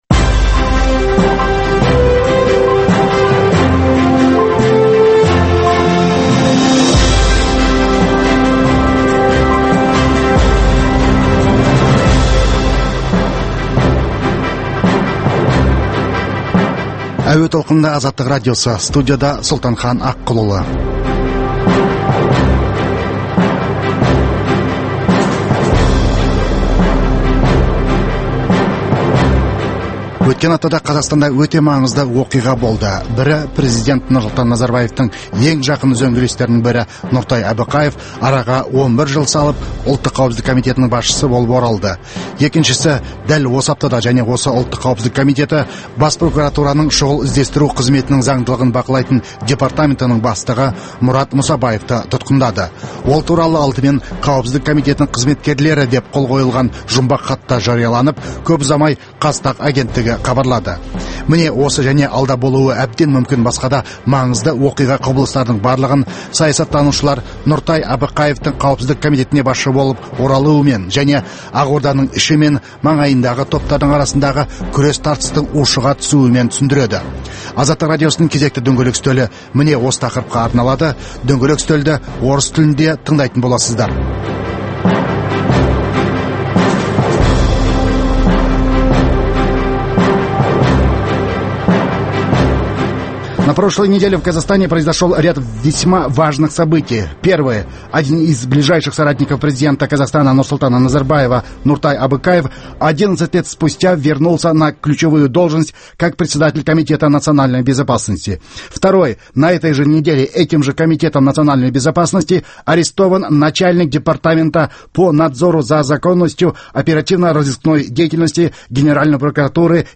Дөңгелек үстел
Азаттық радиосының бүгінгі дөңгелек үстел талқылауына саясаткер Серік Абдрахманов, "Алға" партиясының төрағасы Владимр Козлов, "Ақжол" партиясының төрағасы Әлихан Байменов және Рахат Әлиев қатысып, Қазақстандағы саяси ахуалды талқылады.